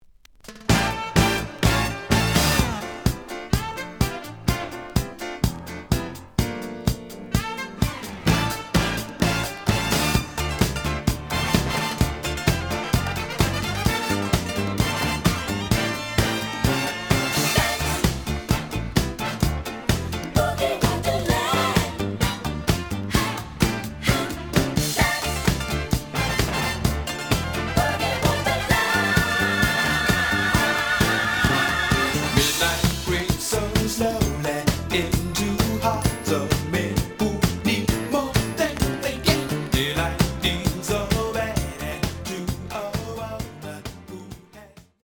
(Stereo)
試聴は実際のレコードから録音しています。
●Genre: Disco